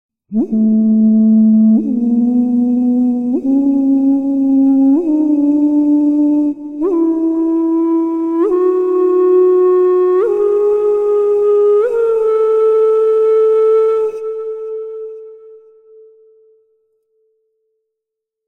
七沢笛　No.0009
吹口：リコーダー形式
キー：Ａ
さて音♪～祠の中にいる感じ・・・